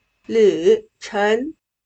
旅程/Lǚchéng/Ruta, itinerario, viaje.